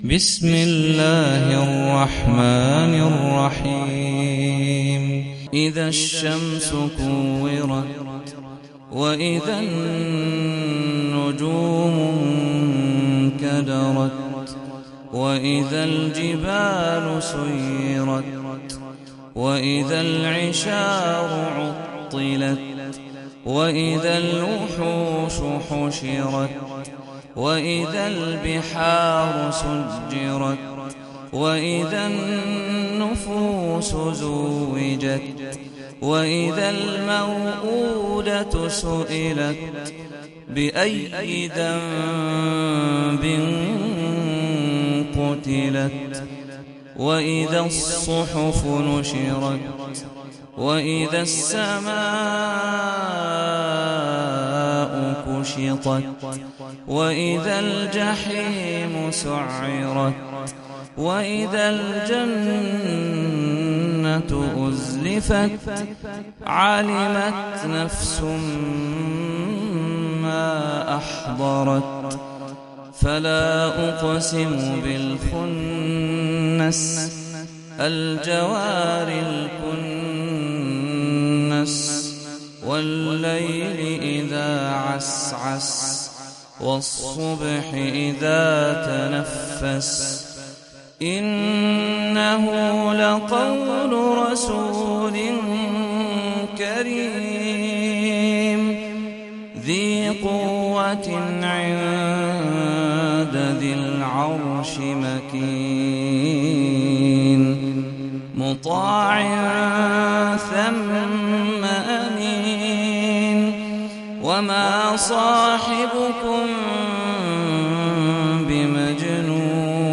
سورة التكوير - صلاة التراويح 1446 هـ (برواية حفص عن عاصم)
(صوت - جودة عالية